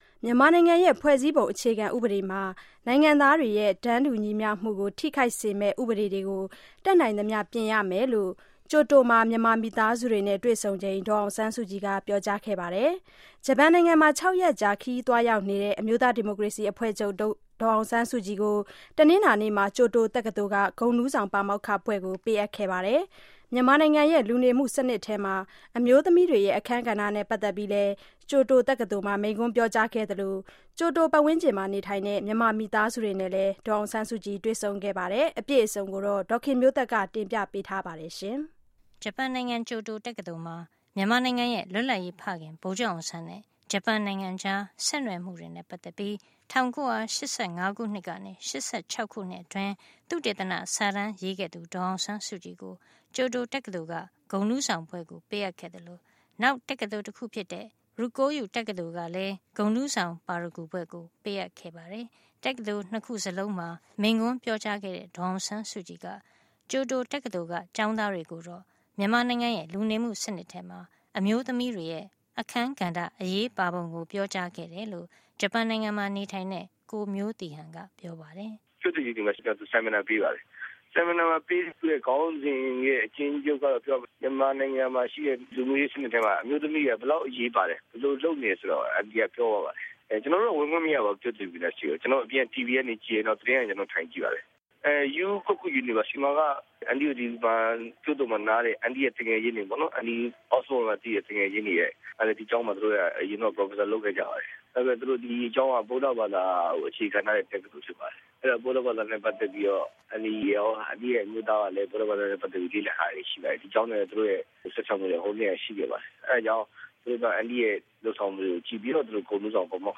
ဒေါ်စု ဂျပန်တက္ကသိုလ်မှာ မိန့်ခွန်းပြော